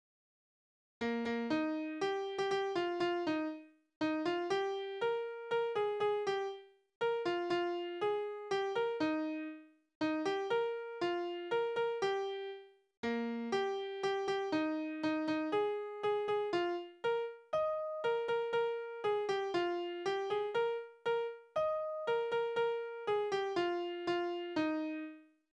Berufslieder:
Tonart: Es-Dur
Taktart: 3/4
Tonumfang: Oktave, Quarte
Besetzung: vokal